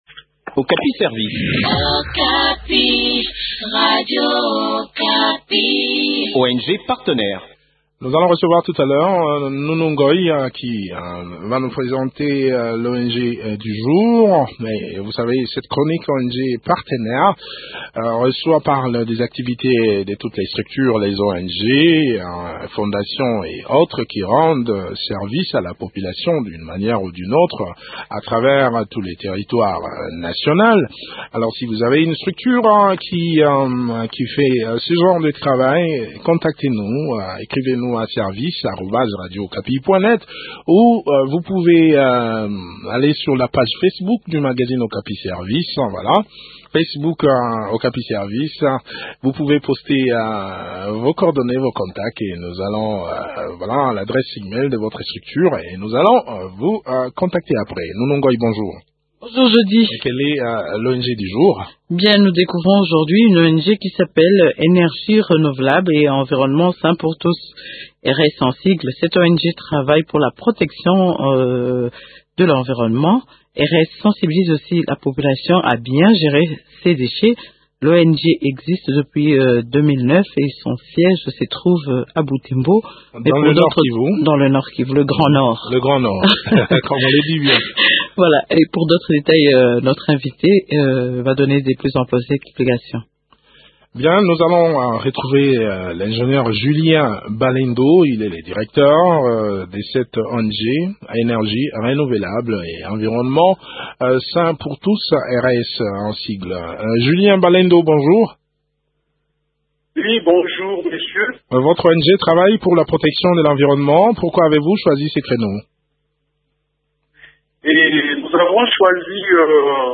fait le point de leurs activités au micro